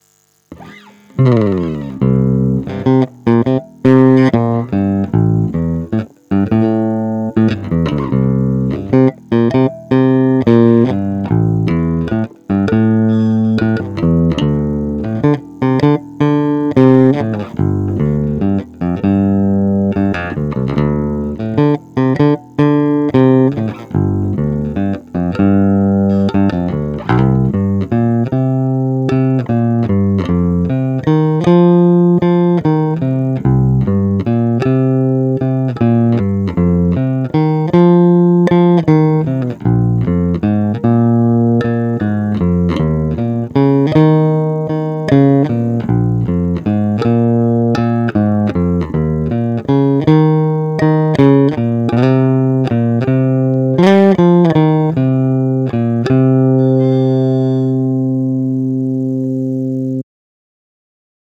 No asi klasický jazz bass J Posuďte sami z nahrávek, které jsou provedeny rovnou do zvukovky bez úprav.
Kobylkový a tonovka na plno
V ukázkách ti trochu sice něco "cvrčí" v pozadí a občas přebuzuje, ale tvoje nahrávky se mi líbí víc než originál z toho videa, kde mi to připadá nahrané dost nechutně s "mid scooped" charakterem (schválně si to poslechněte a porovnejte).